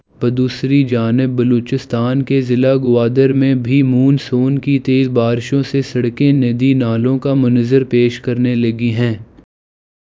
deepfake_detection_dataset_urdu / Spoofed_TTS /Speaker_06 /265.wav